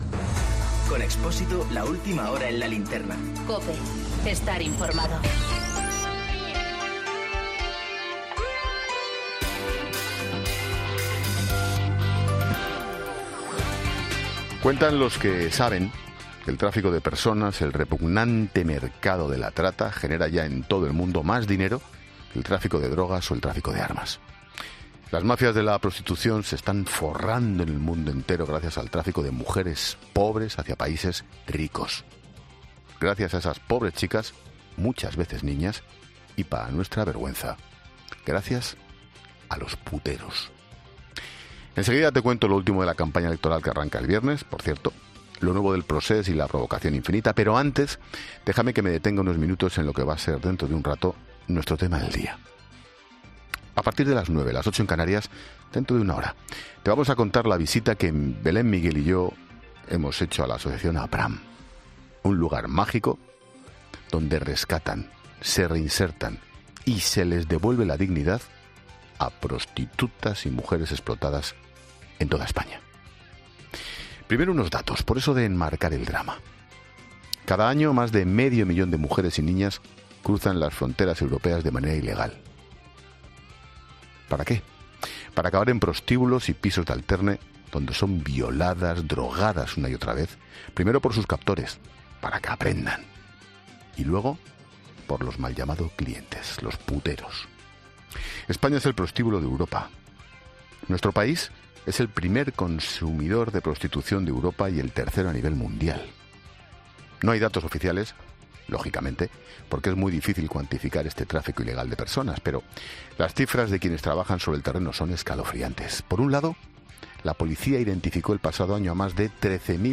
Monólogo de Expósito
El presentador de La Linterna analiza la lacra de la prostitución en España, primer país en cuanto a clientes de toda Europa